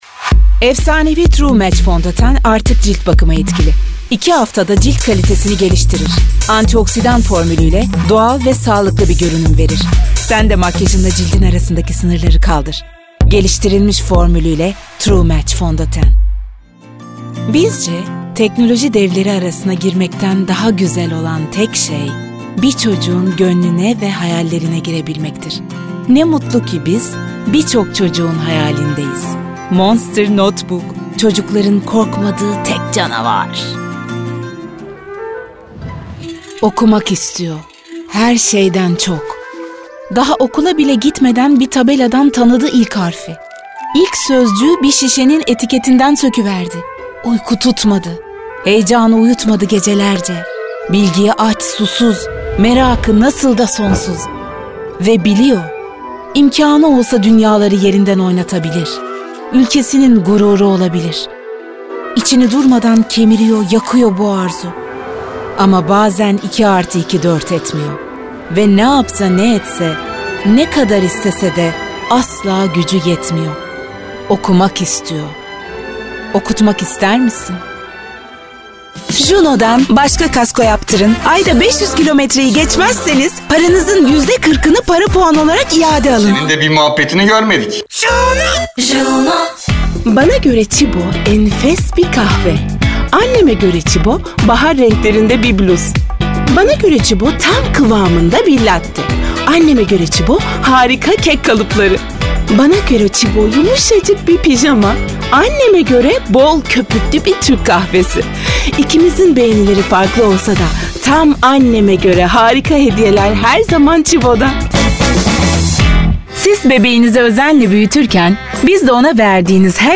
KATEGORİ Kadın
KARAKTER-ANİMASYON Canlı, Eğlenceli, Sakin, Güvenilir, Havalı, Karakter, Seksi, Animasyon, Karizmatik, Promosyon, Sıcakkanlı, Dış Ses,